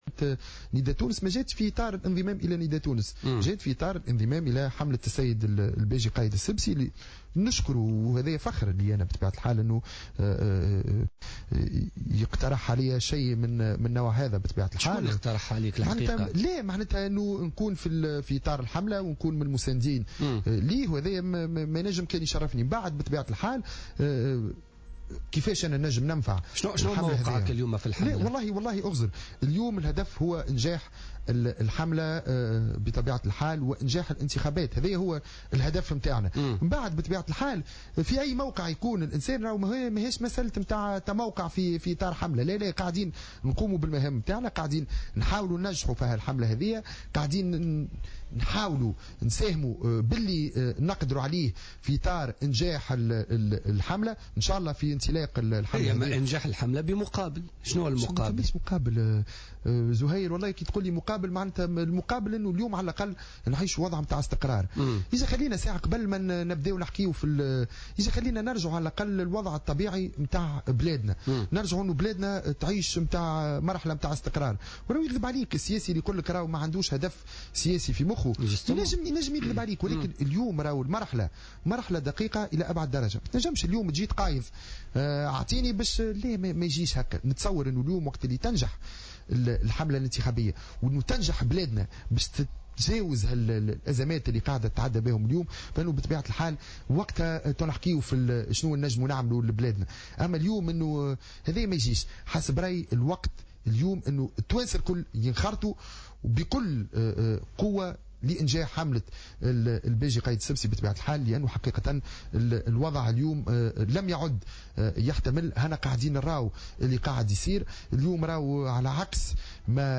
أكد النائب السابق بالمجلس التأسيسي محمود البارودي ضيف برنامج بوليتيكا اليوم الثلاثاء 9 ديسمبر 2014 أن مسألة انضمامه إلى الحملة الإنتخابية للمترشح للرئاسة الباجي قايد السبسي لاتأتي في اطار الانضمام الى نداء تونس على حد قوله.